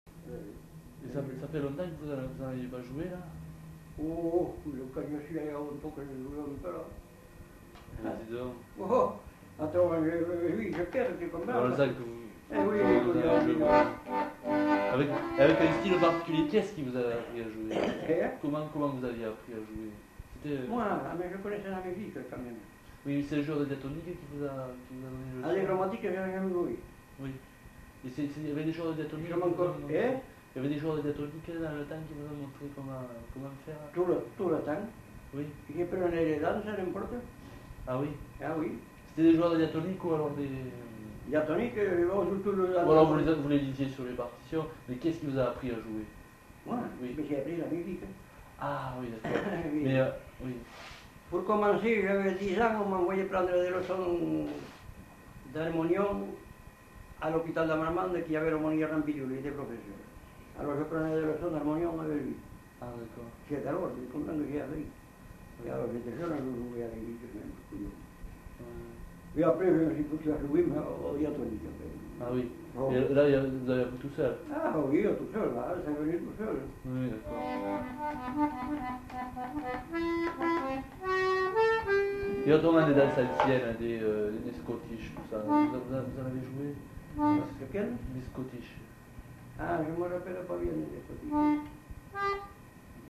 Répertoire d'airs à danser du Marmandais à l'accordéon diatonique
enquêtes sonores